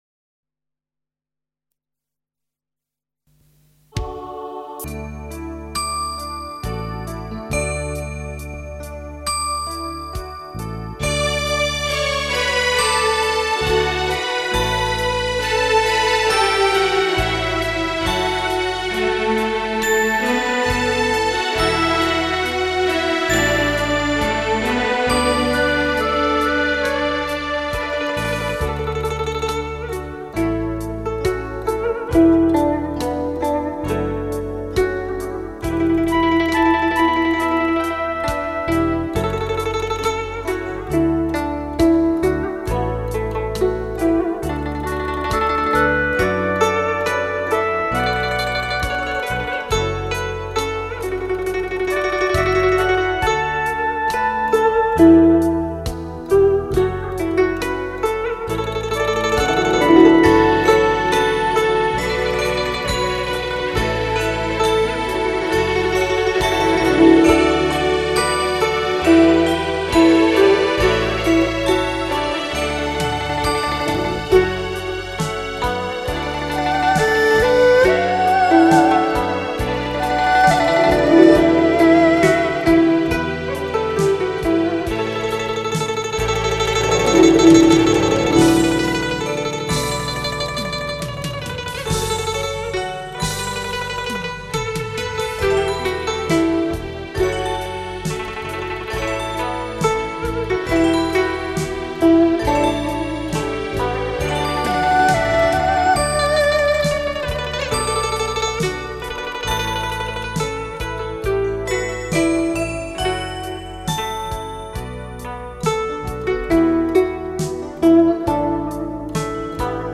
有轻松动听、脍炙人口的民歌精选、有旋律优美，婉转动人的柔情乐曲、有悠扬缠绵、回味难忘的影视插曲
一段乐曲足已把人引到一个世外桃源,尽享民族风情,如乘风驾云赏月,沁人心脾.